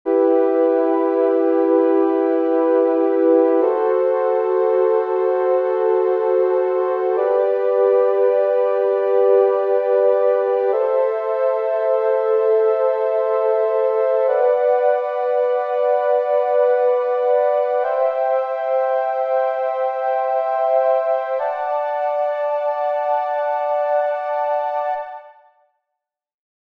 For example, in our scale of E Minor, the E chord becomes E-G-B and B would be B-D-F#, etc. Create a two-bar MIDI file with each chord, with all notes sustained.